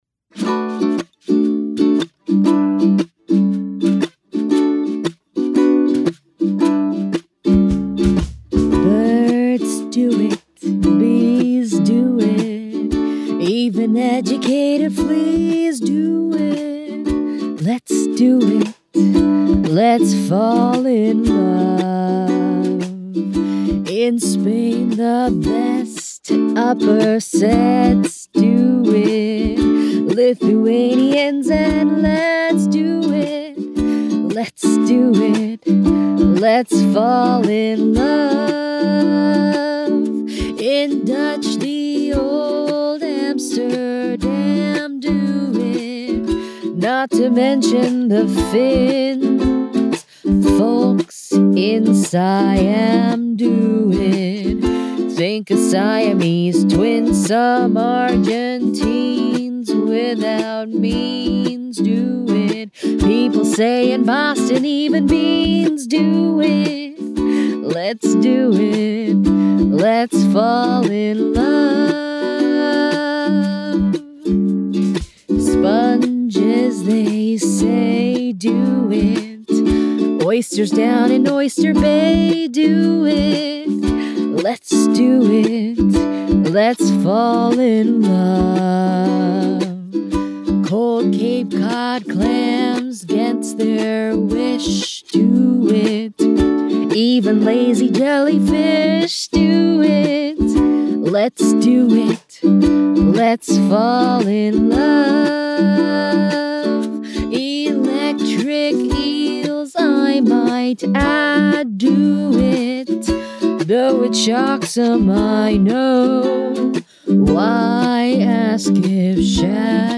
Teenager, Young Adult, Adult
Has Own Studio
singapore | natural
standard us | natural